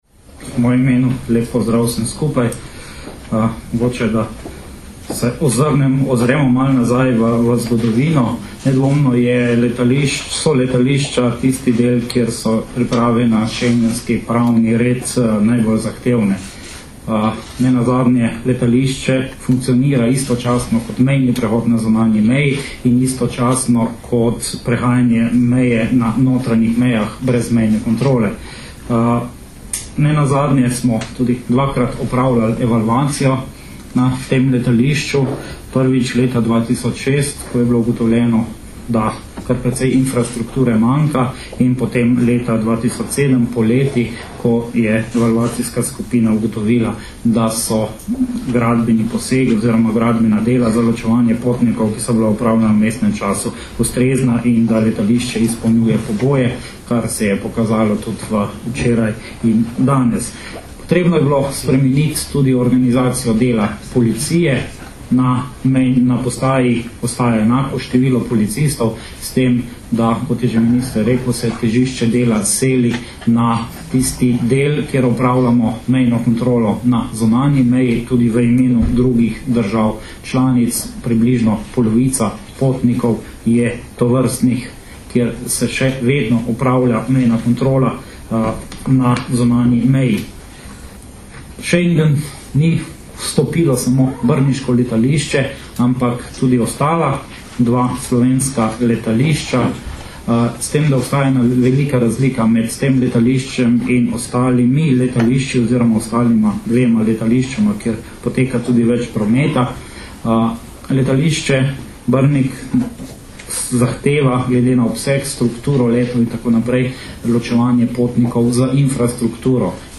Predstavniki Ministrstva za notranje zadeve, Policije in Aerodroma Ljubljana so na današnji novinarski konferenci na letališču Jožeta Pučnika Ljubljana predstavili zgodovinski pomen dogodka za Slovenijo in druge schengenske države, še zlasti pri zagotavljanju varnosti v schengenskem prostoru.
Zvočni posnetek izjave Marka Gašperlina (mp3)